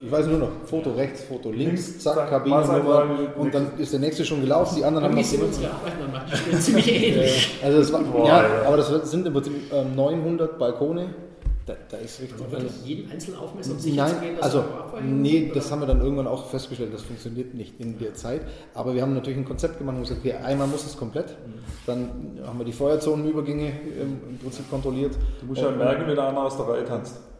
Weitere Teile der Serie "Interview mit den Innenausbau-Spezialisten